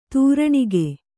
♪ tūraṇige